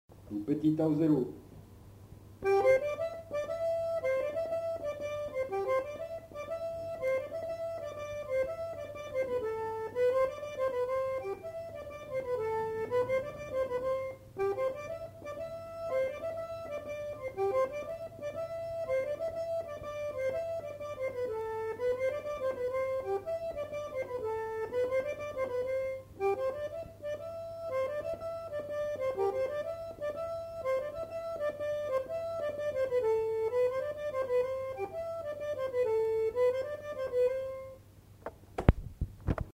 Aire culturelle : Gascogne
Genre : morceau instrumental
Instrument de musique : accordéon chromatique
Danse : courante